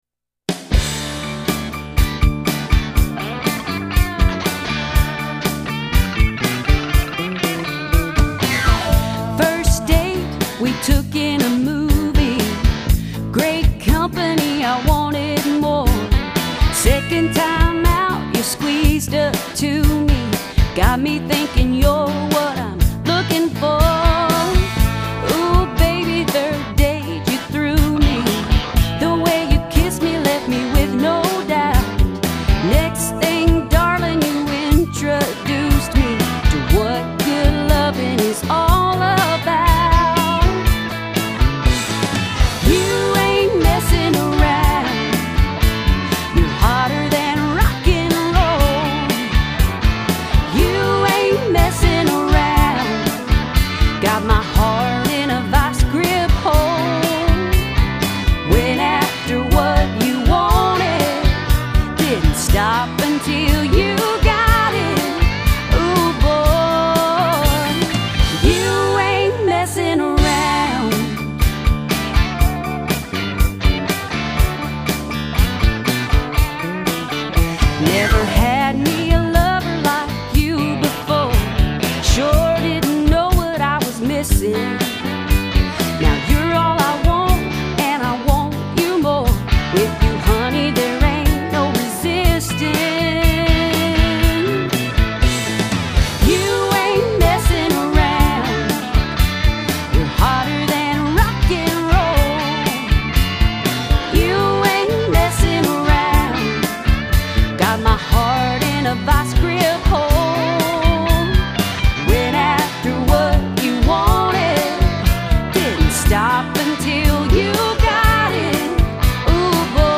Complete Demo Song, with lyrics and music: